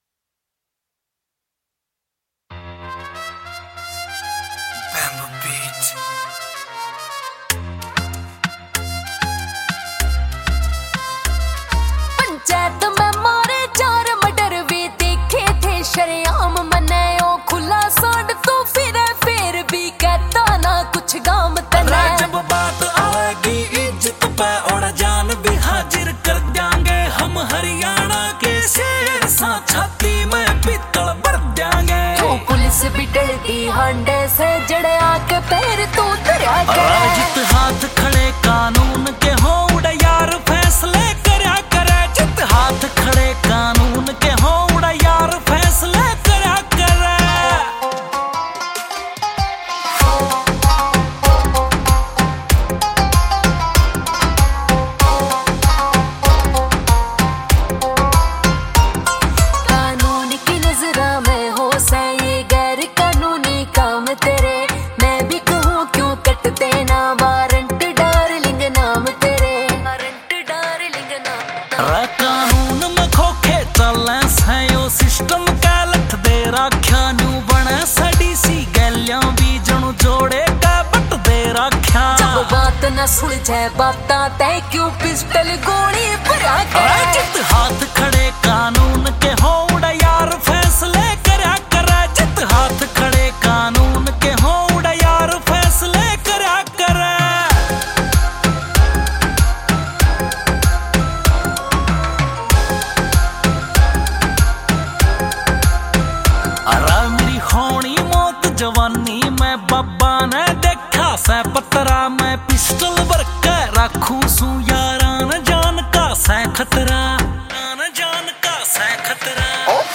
Haryanvi